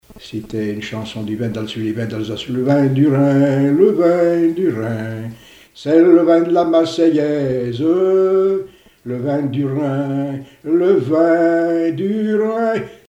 Genre strophique
chansons et témoignages parlés
Pièce musicale inédite